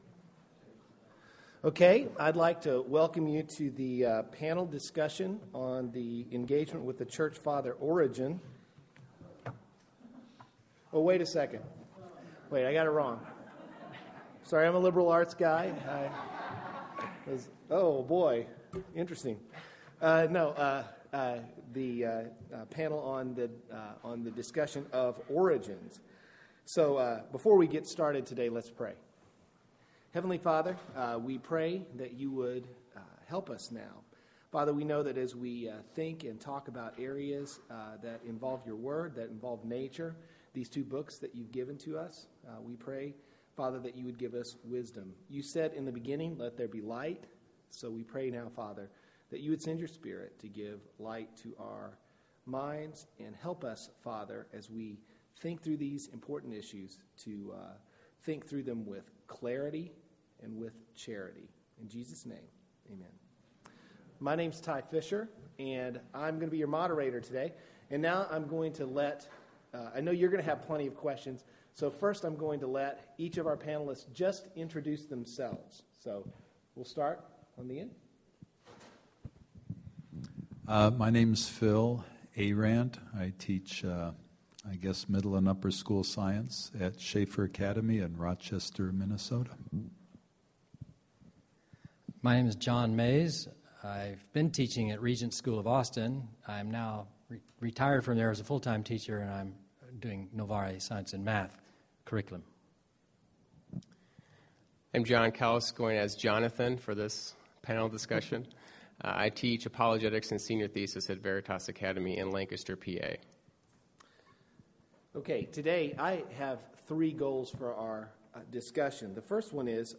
Faithful Engagement with Differing Views of Origins – Panel Discussion | ACCS Member Resource Center
2014 Workshop Talk | 1:00:08 | Science
The Association of Classical & Christian Schools presents Repairing the Ruins, the ACCS annual conference, copyright ACCS.